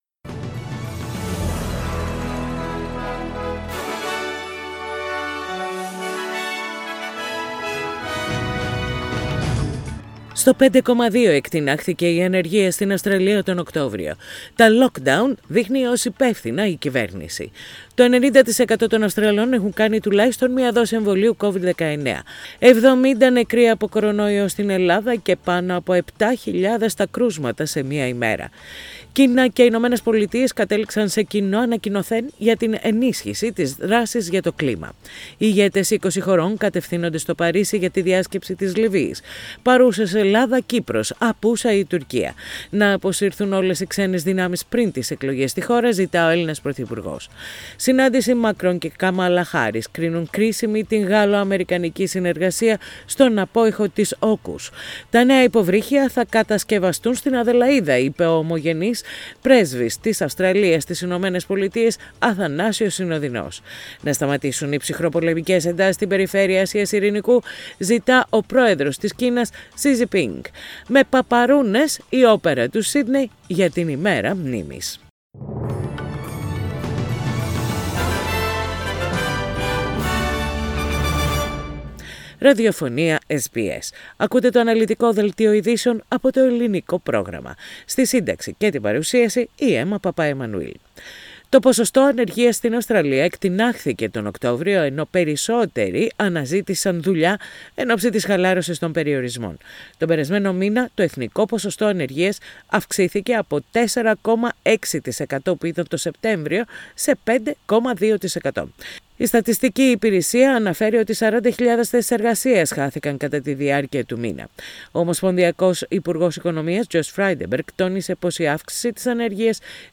Ειδήσεις στα Ελληνικά - Πέμπτη 11.11.21